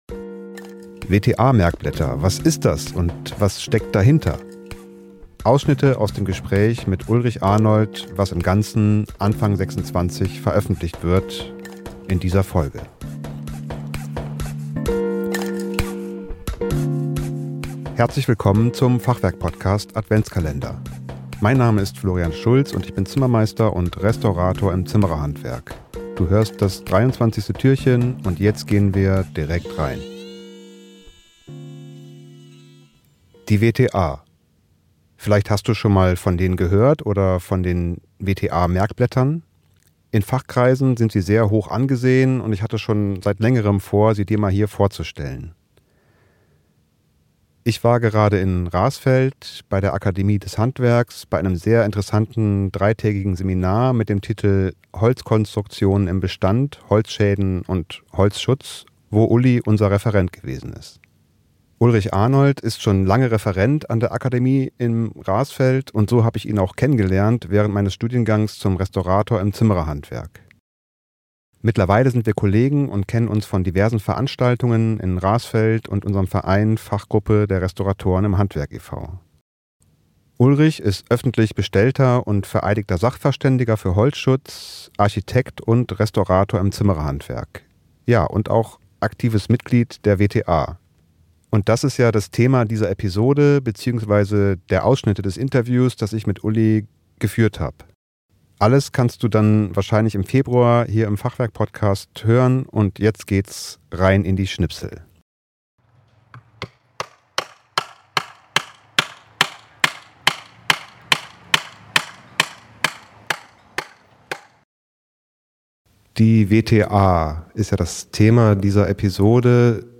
WTA Merkblätter - Ausschnitte aus dem Interview